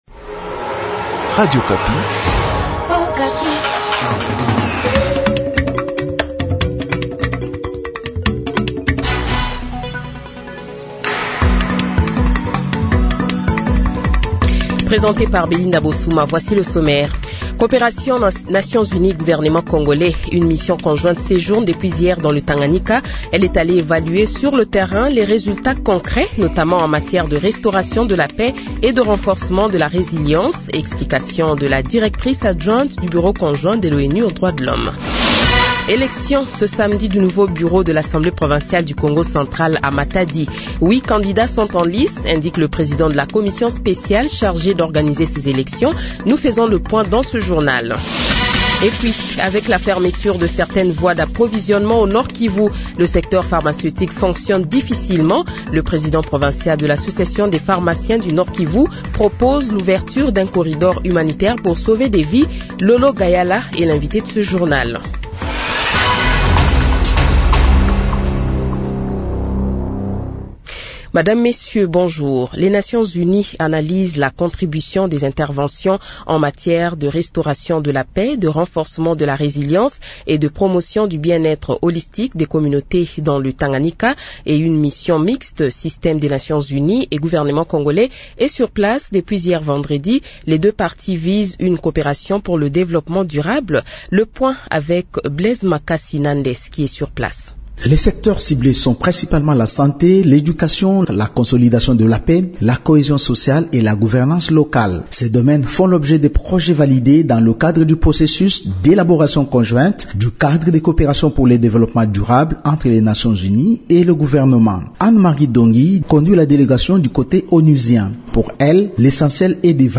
Journal Francais Midi